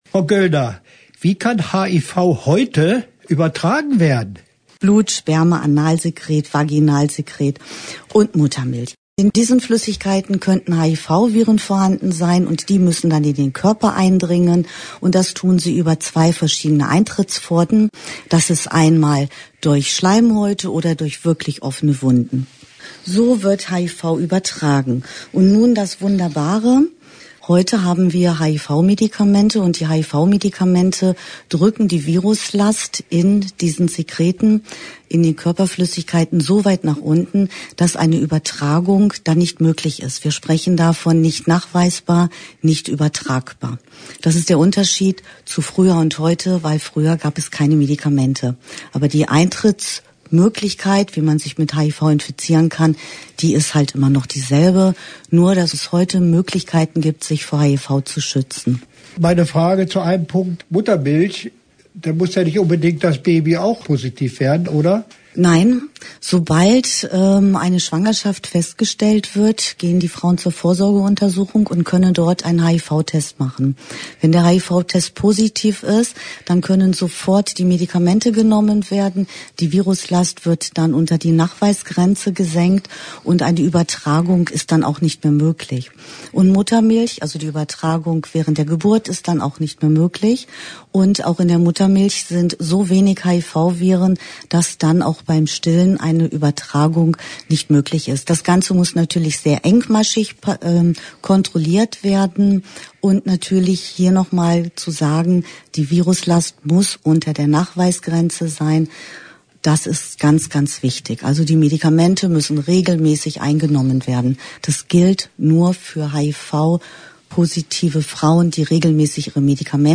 Interview-HIV-Frauenselbsthilfe.mp3